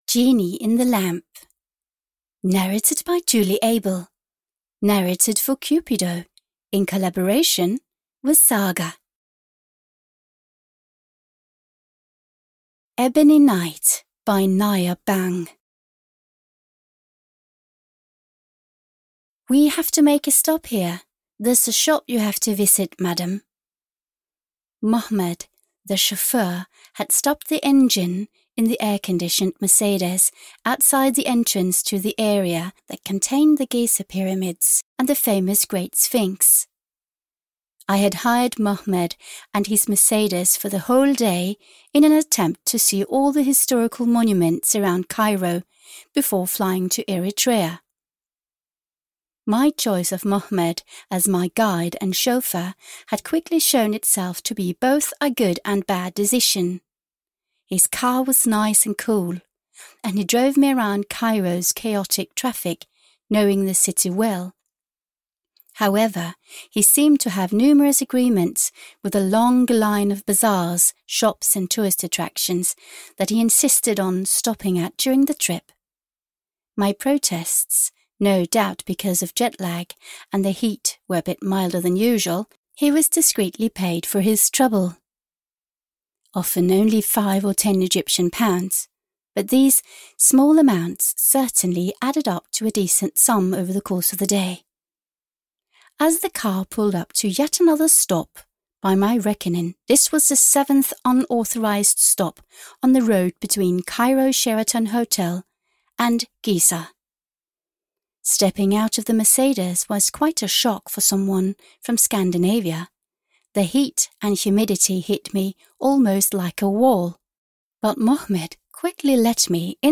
Genie in the Lamp (EN) audiokniha
Ukázka z knihy